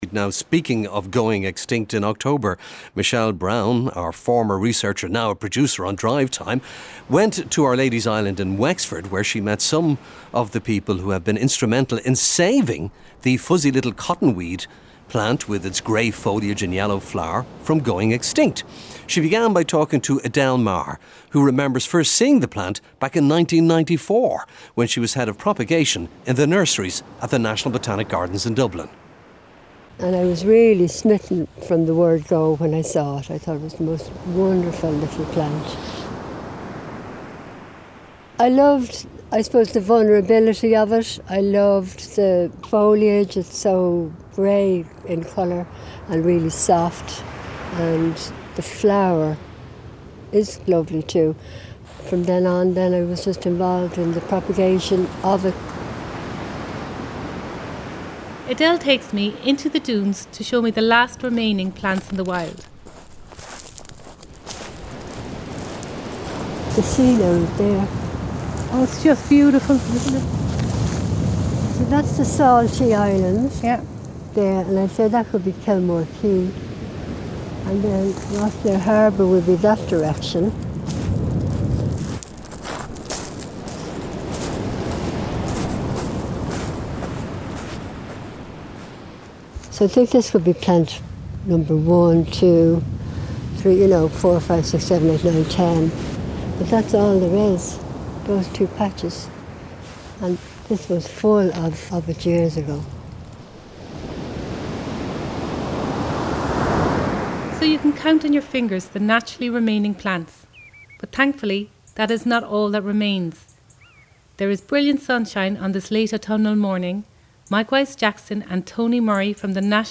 Derek Mooney and guests explore the natural world in all its forms.